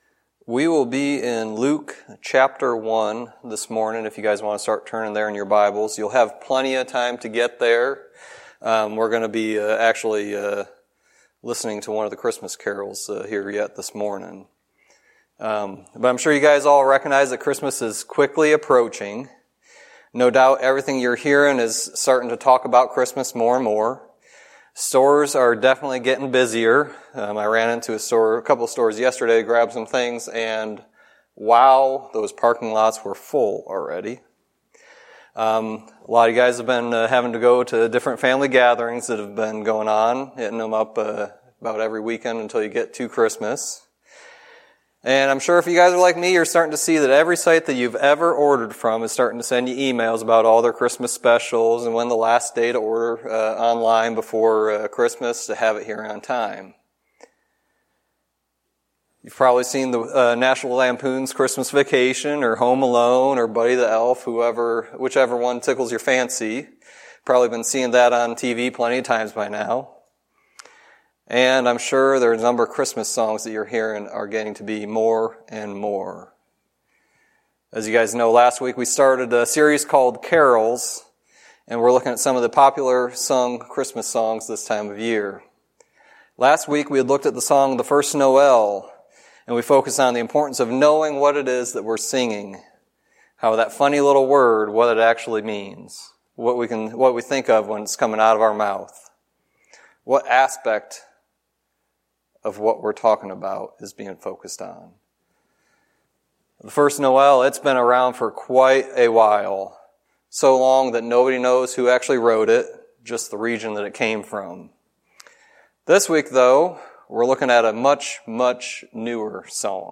Sermon messages available online.